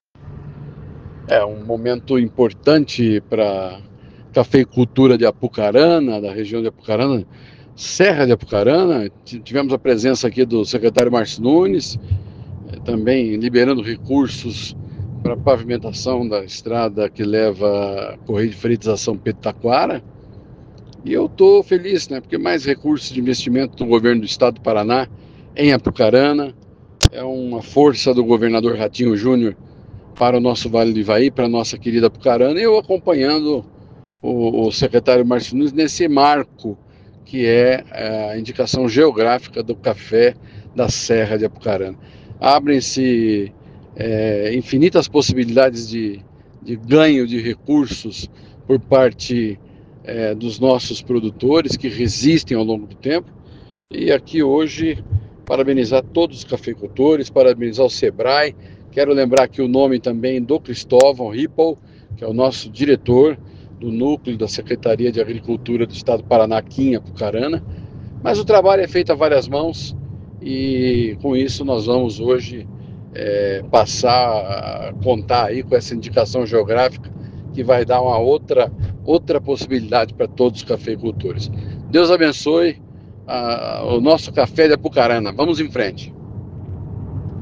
Beto Preto – Secretário de Estado da Saúde